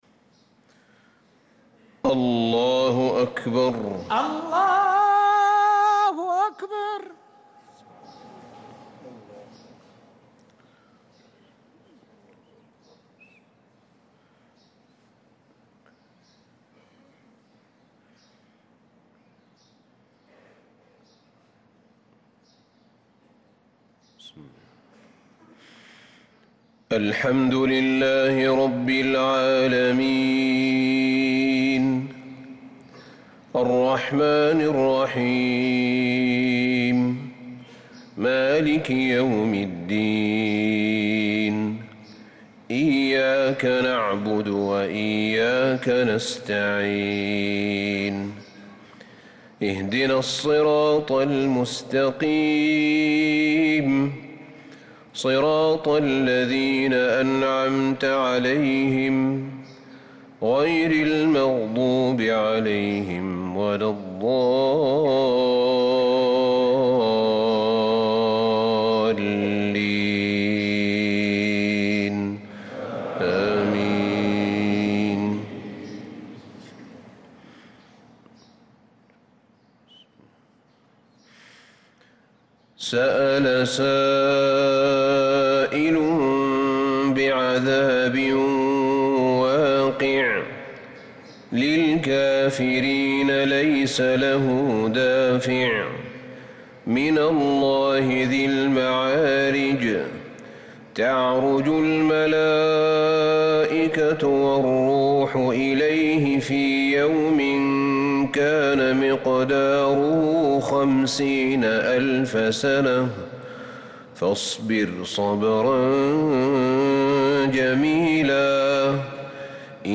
صلاة الفجر للقارئ أحمد بن طالب حميد 8 ربيع الأول 1446 هـ
تِلَاوَات الْحَرَمَيْن .